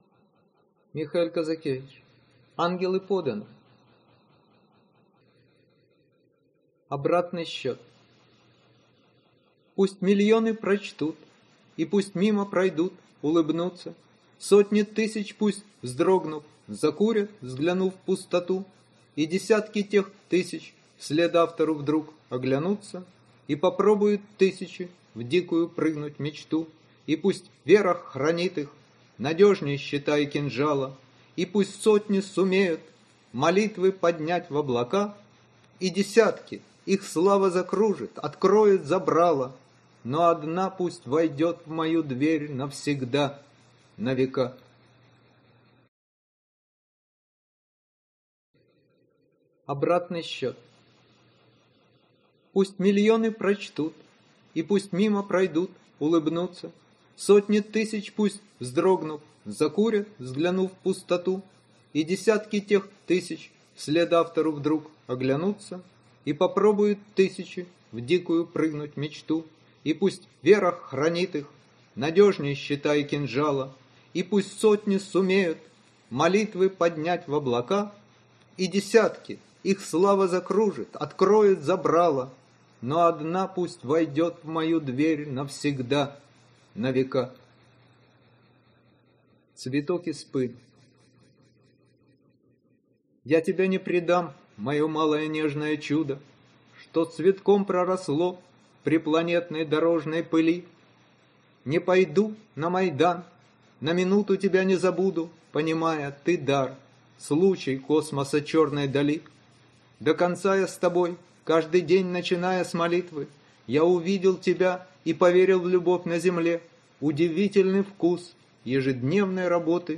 Аудиокнига Ангелы поданы…